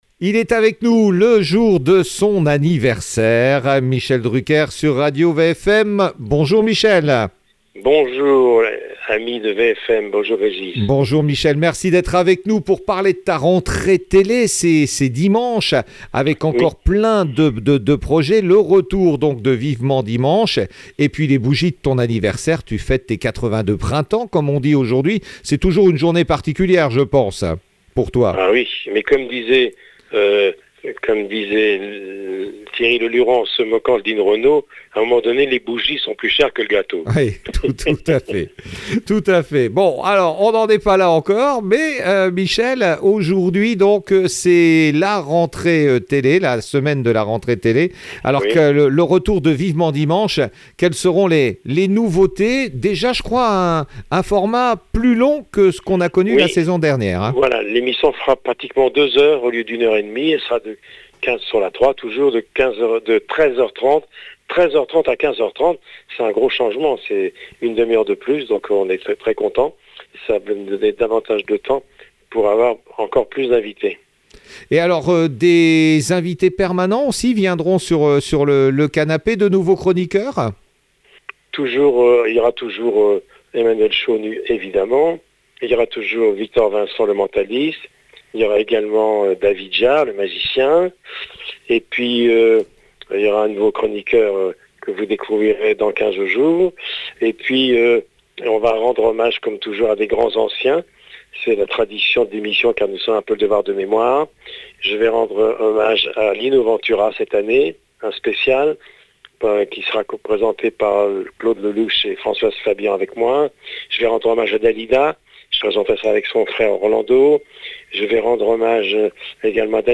9 min 25 sec Michel Drucker fait sa rentrée sur Radio Vfm Retrouvez Michel Drucker en interview sur Radio Vfm,il nous parle de sa rentrée sur france 3 dans Vivement dimanche.